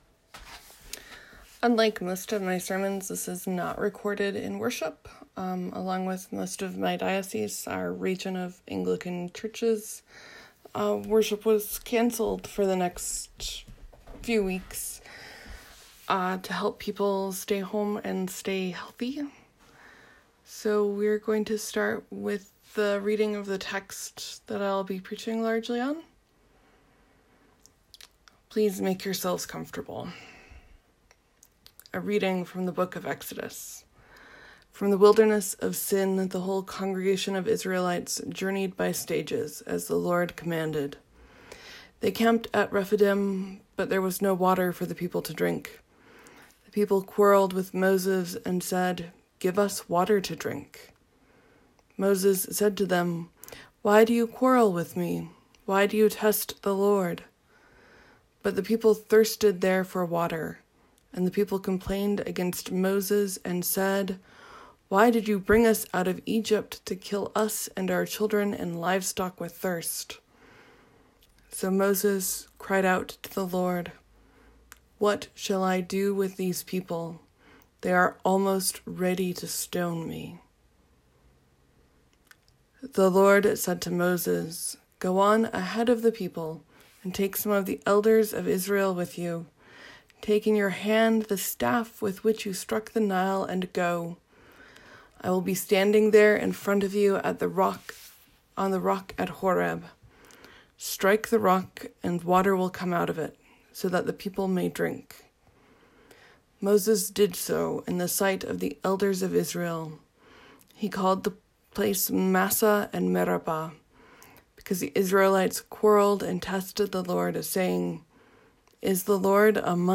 As I wasn’t preaching in worship, the recording begins with me reading the passage I worked most closely with.